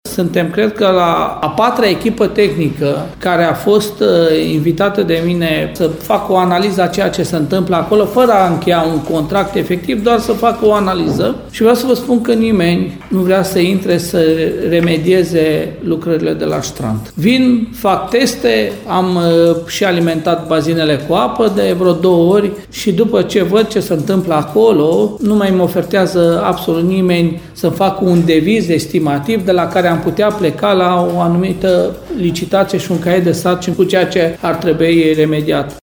Primarul Călin Dobra spune că mai multe echipe tehnice au refuzat să se implice în remedierea problemelor de strand.